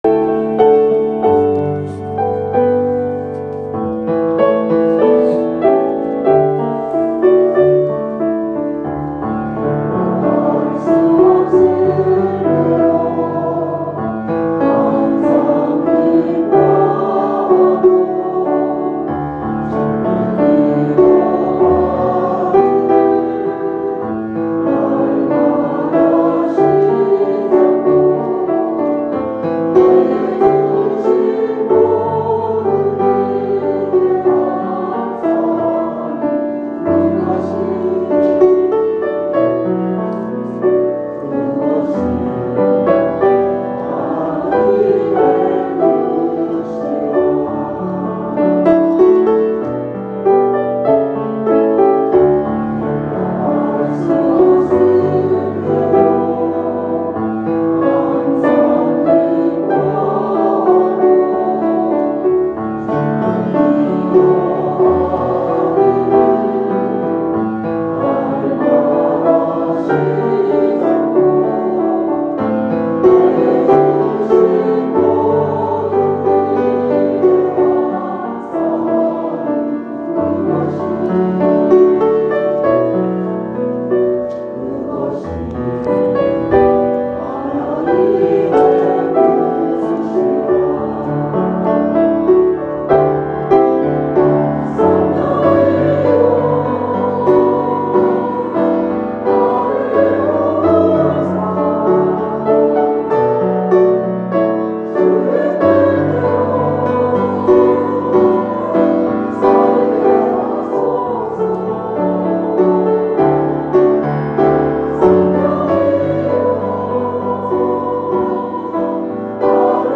Rejoice & Jublilee Choir
2021년 11월 28일 주일 쥬빌리 찬양대